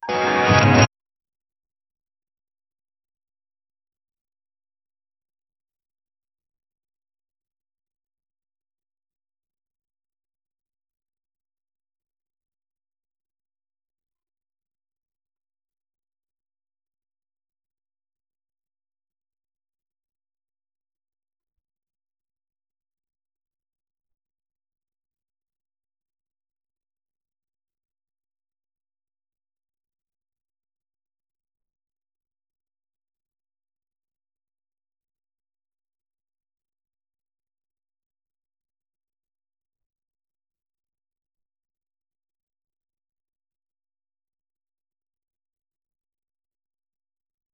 Royalty-free Sci-Fi sound effects
instant-teleport-lulkr6pe.wav